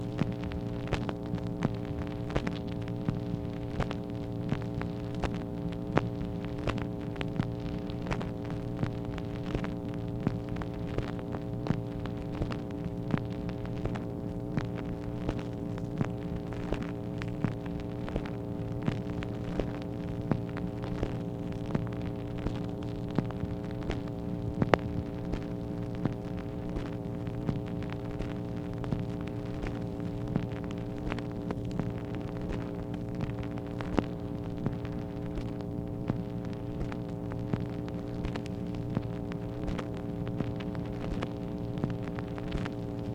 MACHINE NOISE, August 15, 1964
Secret White House Tapes